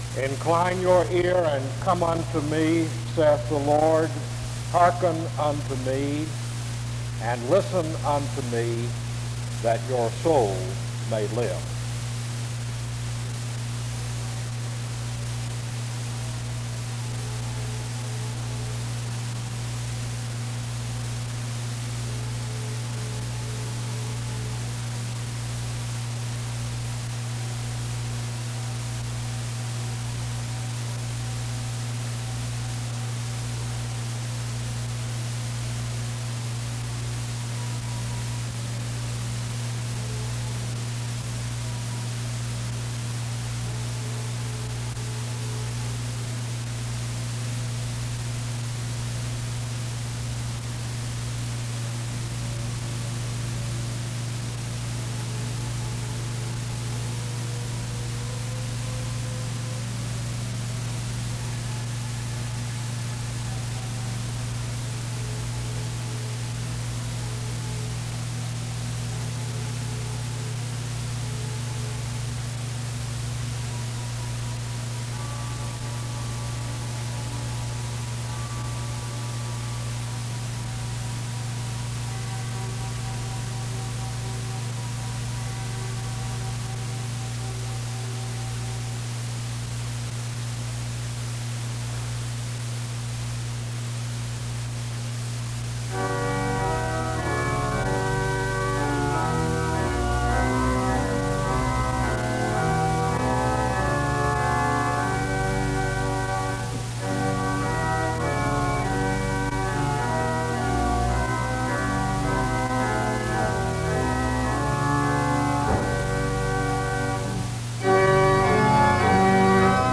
The service starts with scripture reading and music from 0:00-2:14. A word of prayer is offered from 2:18-4:49.
Special music plays from 4:59-9:13.